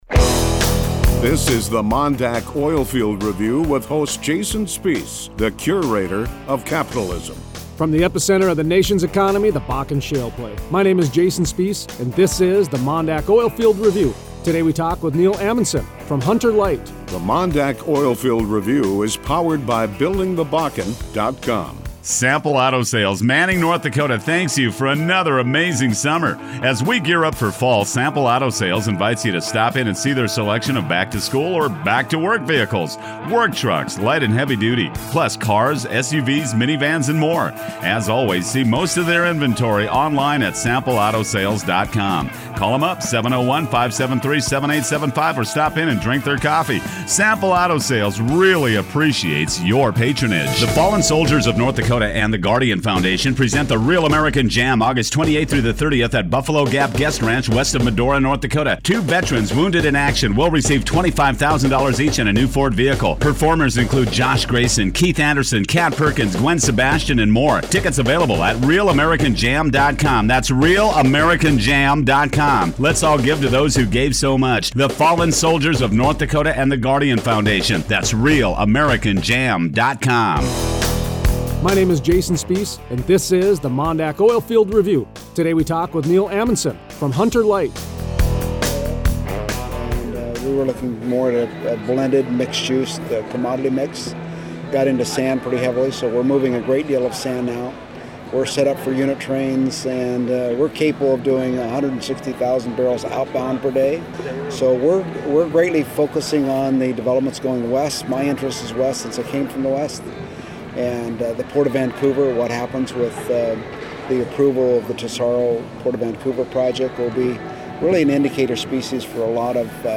Friday 8/7 Interview